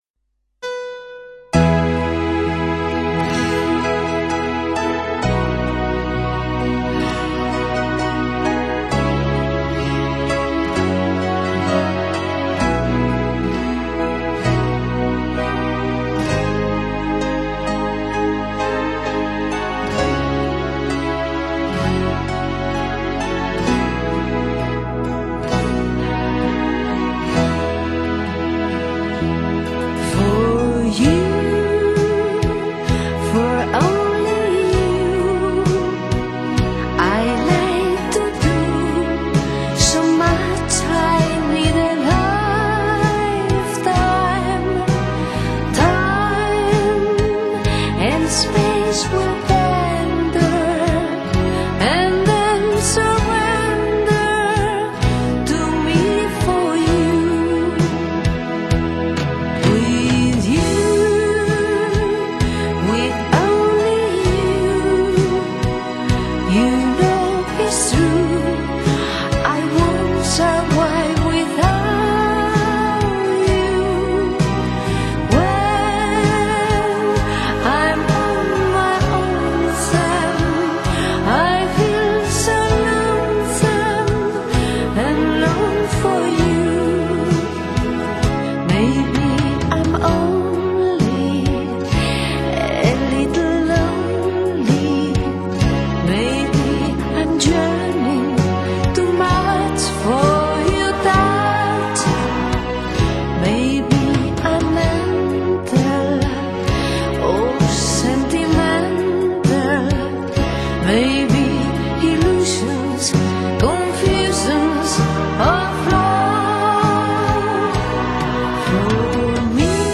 当时的风格是Pop-Disco或者通俗的说成Classical-Disco。